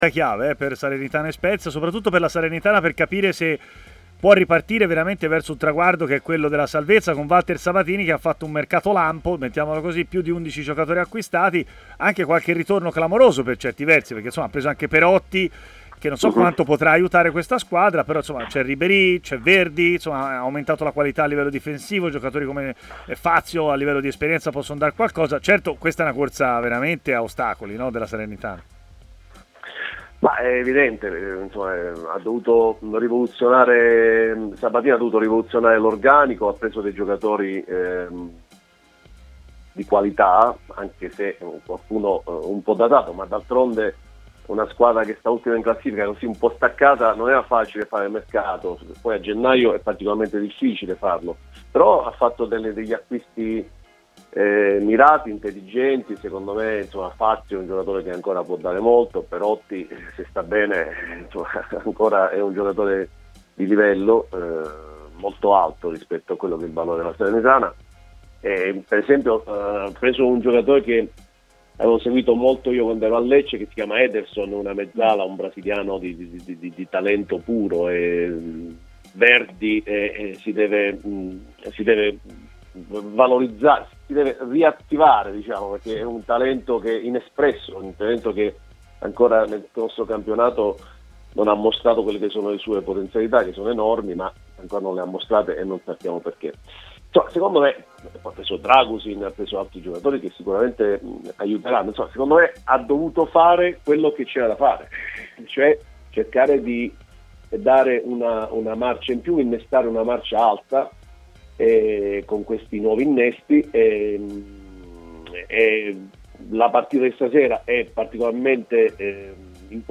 in diretta a TMW Radio, durante la trasmissione Stadio Aperto